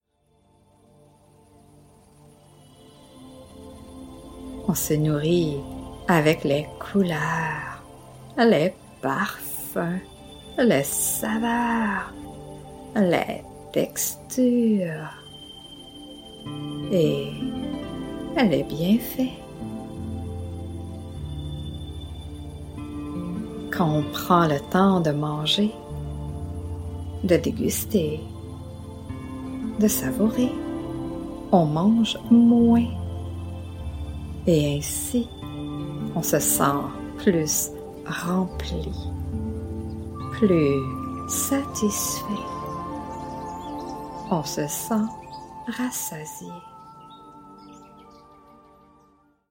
Ce mp3 d'hypnose vous permet de faire de meilleurs choix alimentaires.